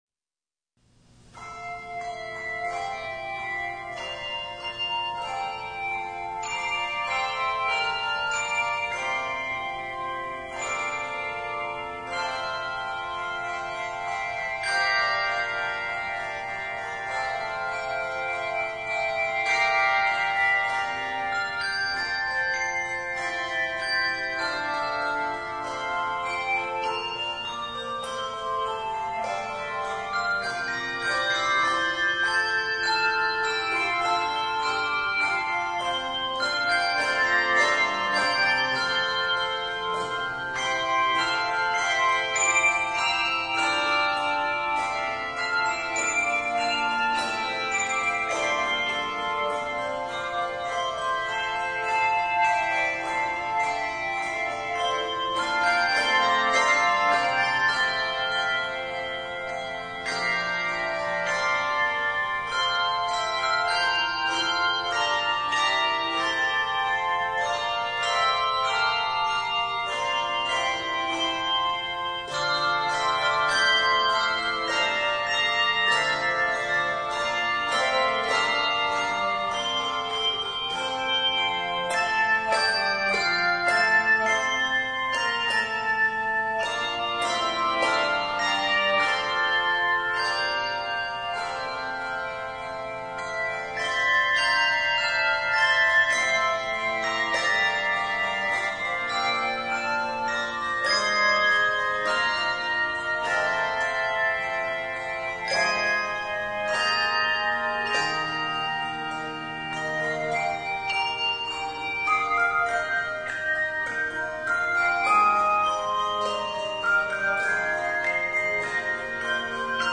Voicing: Handbells 3-5 Octave